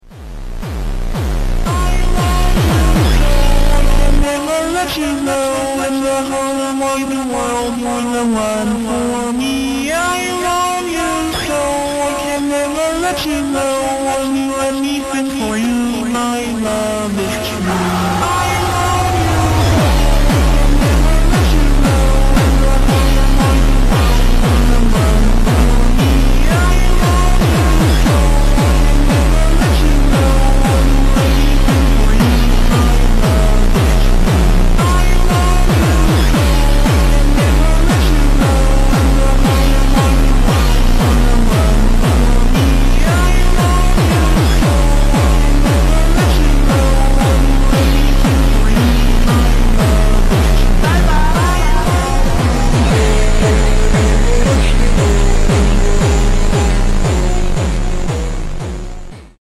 JUMPSTYLE (slowed)